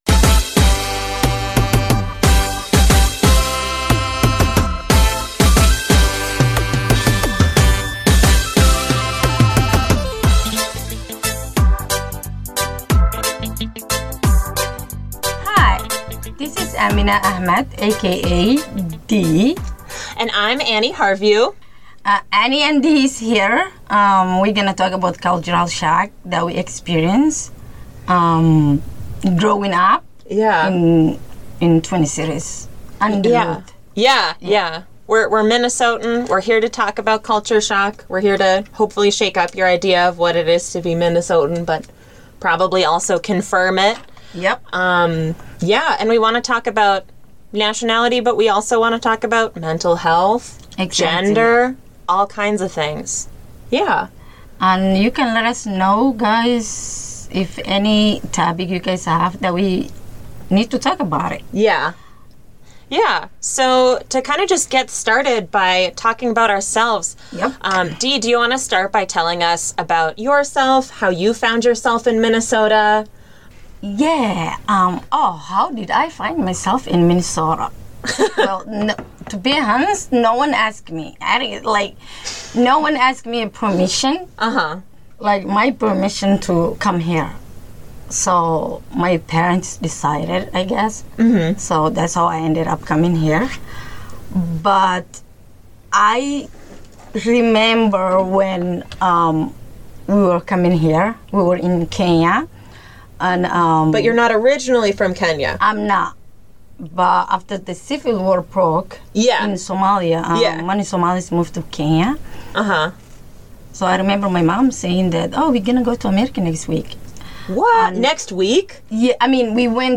This story has to be told in-person, so the laughs and the pain are coexisting right there.